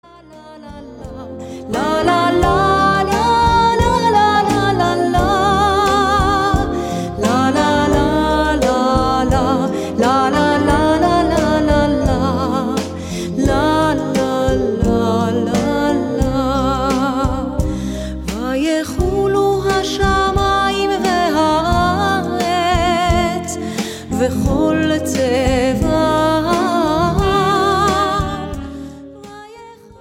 High Holy Day music
Traditional style with a modern flavor.